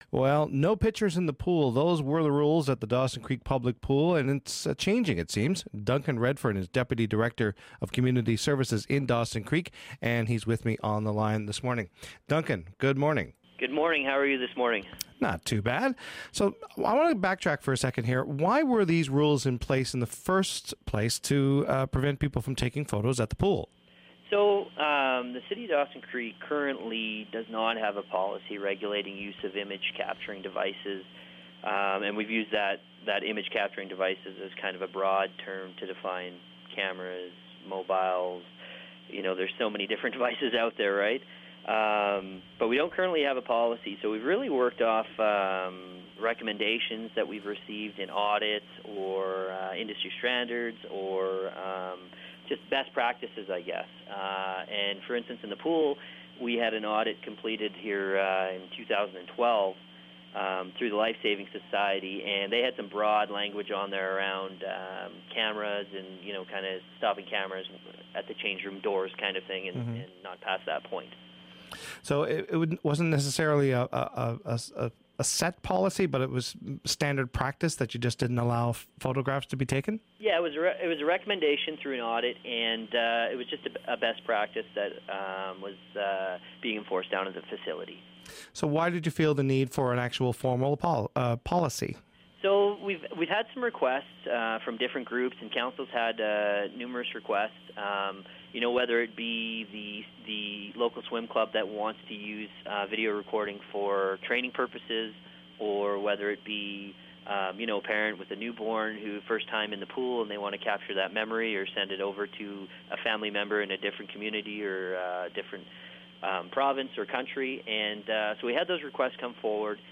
The city of Dawson Creek currently bans cameras and cellphones from the public pool in order to protect privacy, but now that is changing. Listen to the conversation